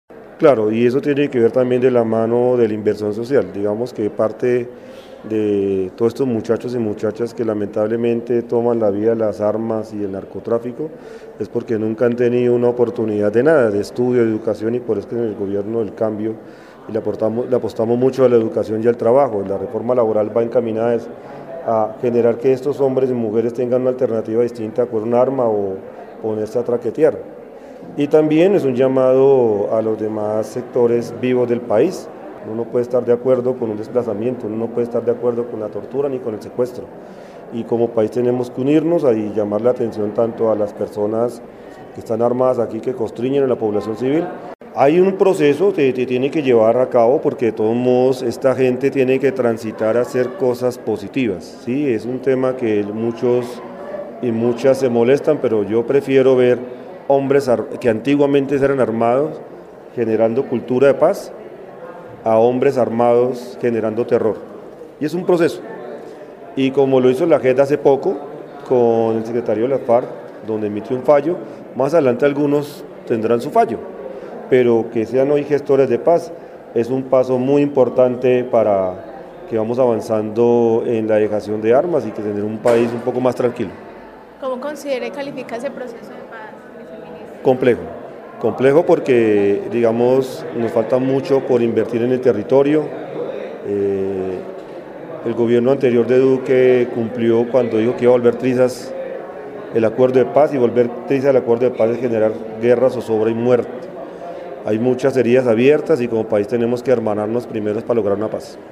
Viceministro para el diálogo social, Gabriel Rondón
En el marco de un foro de derechos humanos que se llevó a cabo en el centro de convenciones de Armenia hubo pronunciamiento sobre la situación de la paz del país.